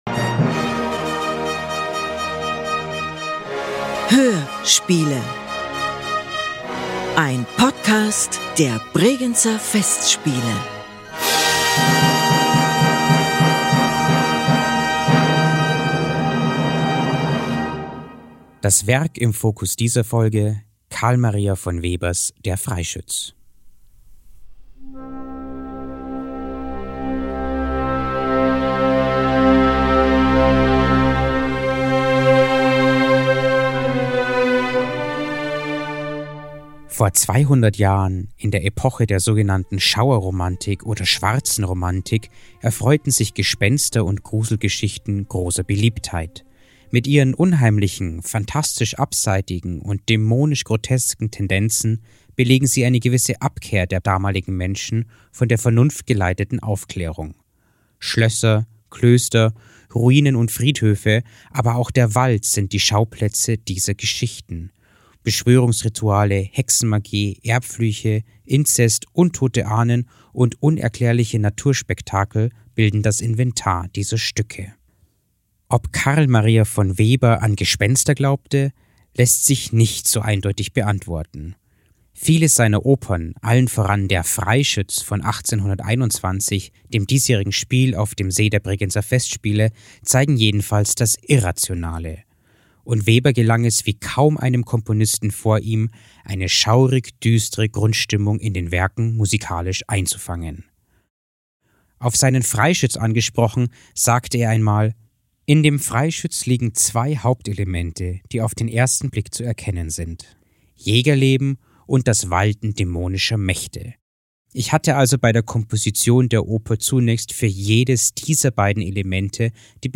Untermalt mit der emotionsgeladenen und packenden Musik erhalten Sie einen Einblick in Webers schaurig-schönes Meisterwerk.